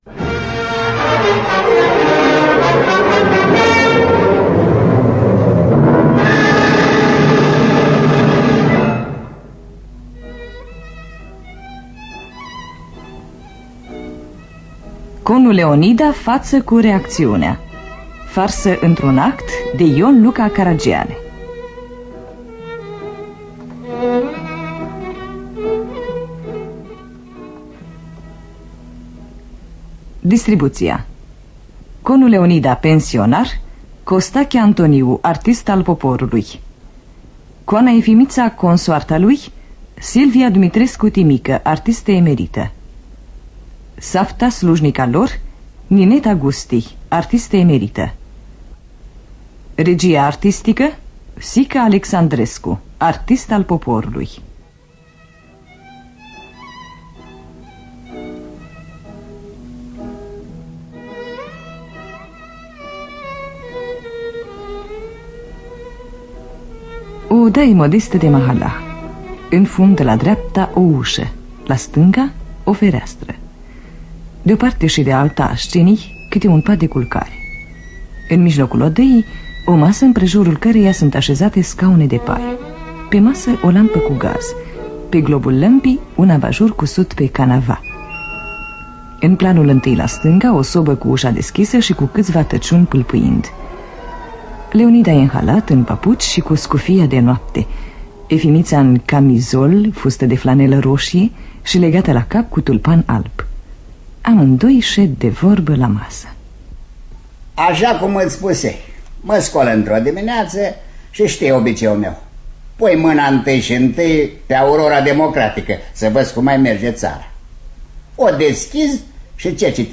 Conu Leonida faţă cu reacţiunea de Ion Luca Caragiale – Teatru Radiofonic Online
În distribuţie: Costache Antoniu, Silvia Dumitrescu-Timică, Nineta Gusti.